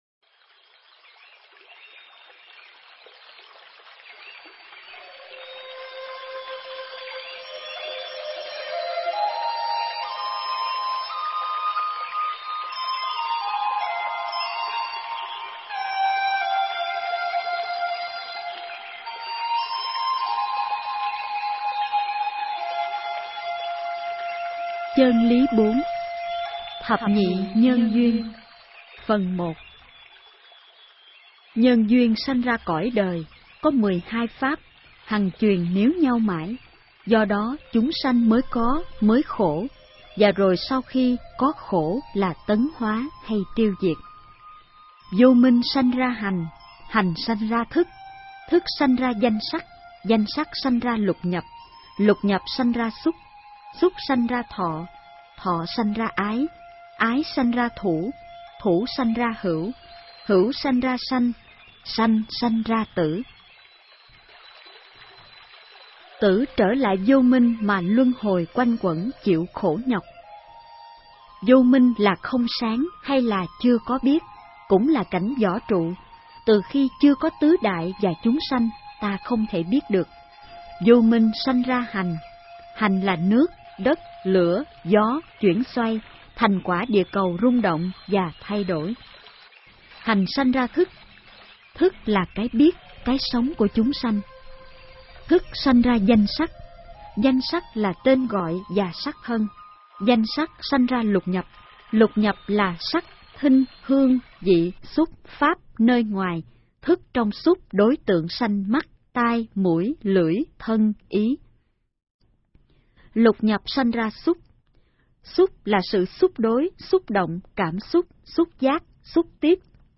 Nghe sách nói chương 04. Thập Nhị Nhơn Duyên